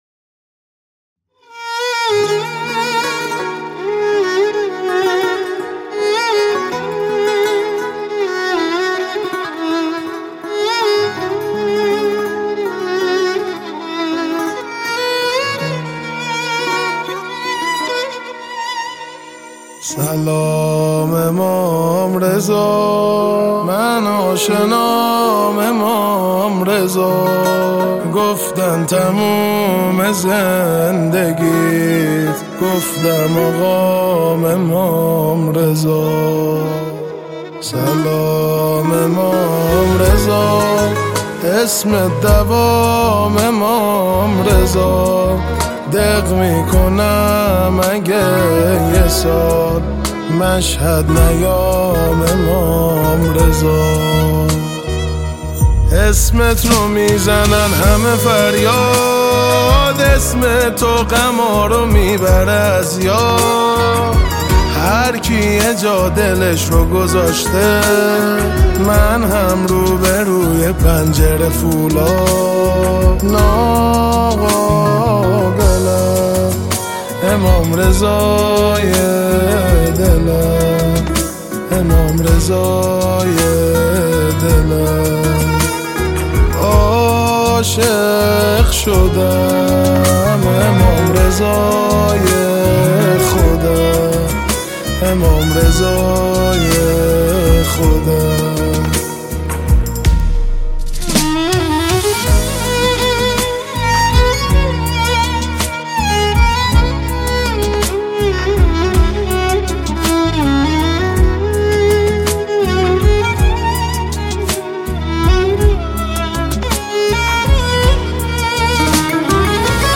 نماهنگ بسیار زیبا و شنیدنی
صدای دلنشین